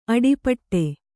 ♪ aḍipaṭṭe